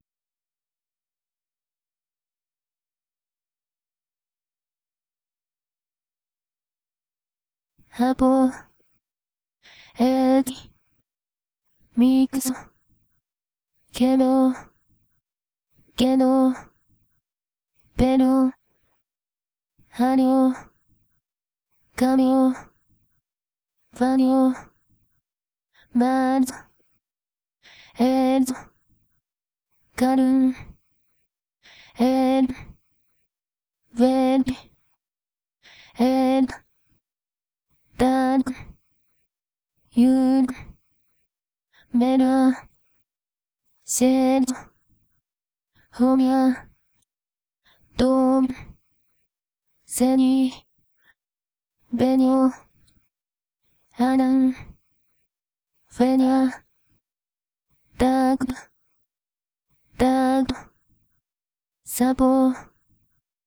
Final consonant cluster tests with Japanese voicebanks in SynthV technical preview versus production release
Setup: 4 bars of rest, followed by one Arka word every bar with 28 words total. No hacks to try to make it work.